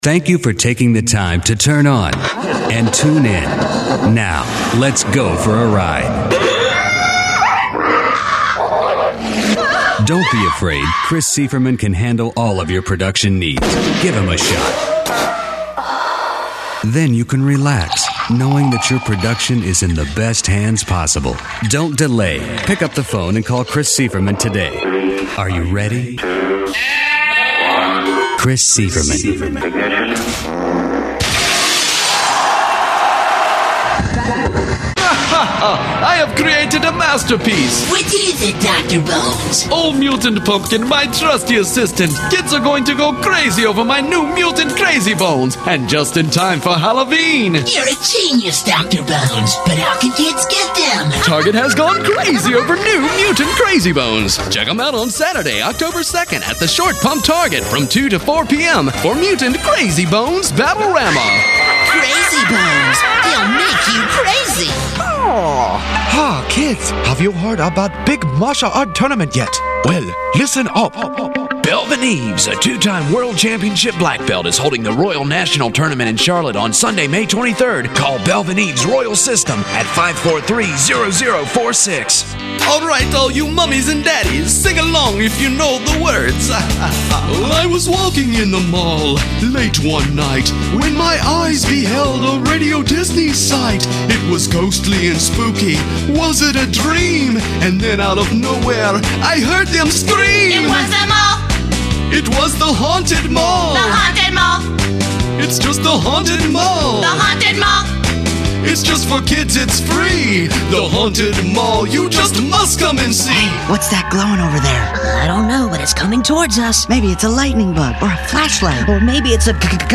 In addition to being the voice of all the male characters, I wrote, produced, and engineered all of these spots.  In the jingles I have included, I play all instruments, sing every part, and produced these "original scores".